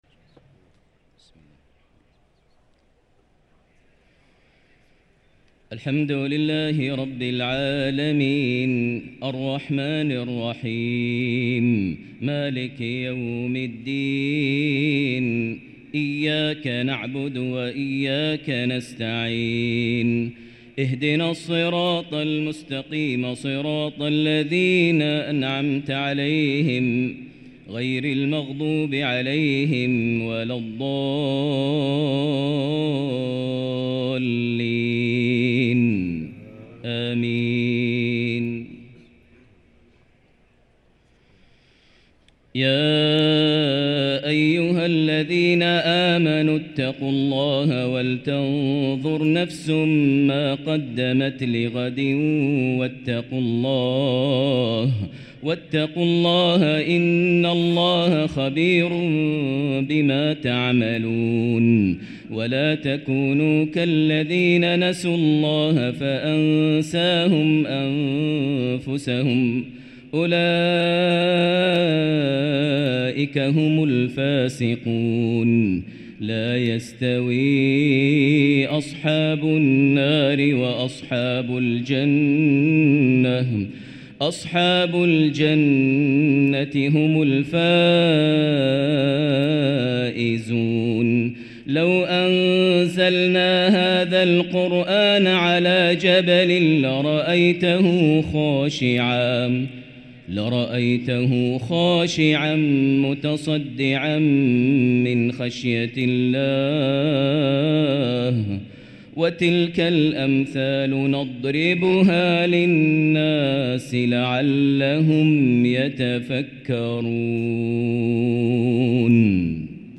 صلاة العشاء للقارئ ماهر المعيقلي 17 رمضان 1444 هـ
تِلَاوَات الْحَرَمَيْن .